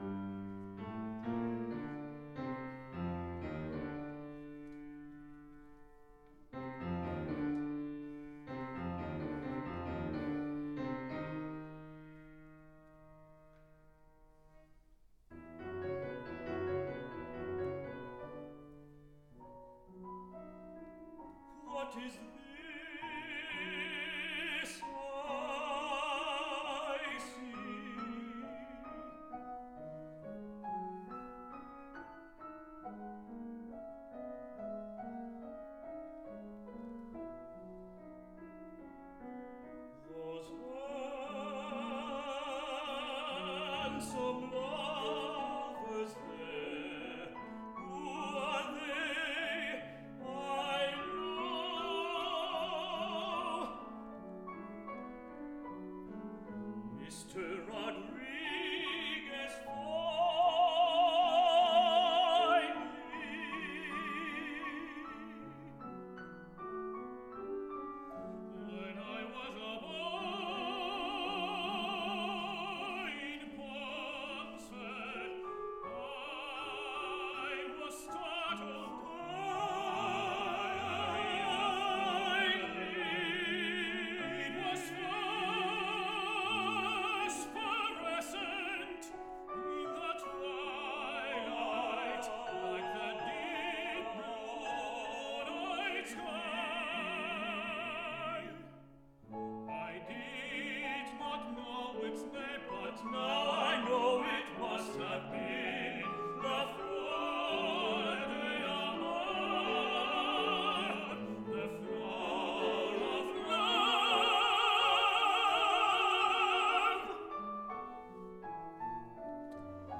recorded at Opera Fusion in Cincinatti, Ohio.